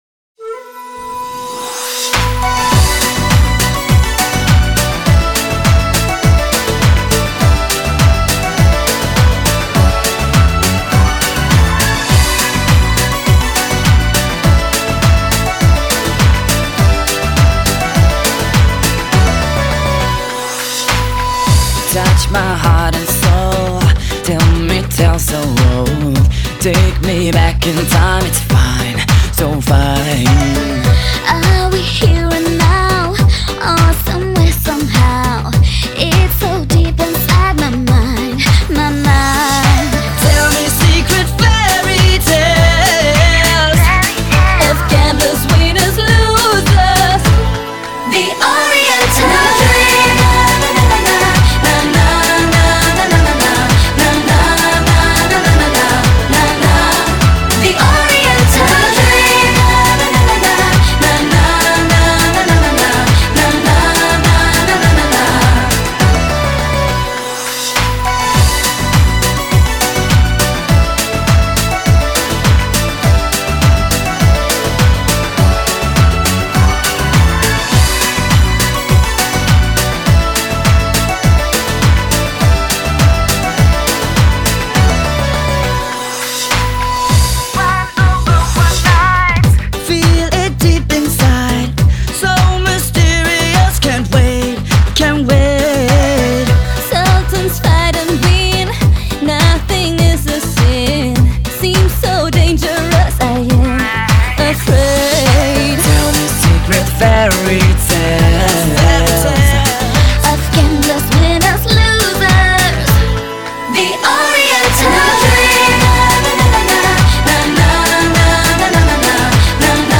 最完美全新混音编配，和最酷炫的慢嗨电音效果
重磅真空登陆，点燃全球各大夜店派对的潮流摇摆音浪，华丽震撼的律动节奏，全球夜场最热DJ舞曲，
最HIGH-慢摇-劲爆舞曲。
娇艳的鼓点，炽热的节奏让空气中也充满性感，让你无法抵挡，动感串烧舞曲的魅力，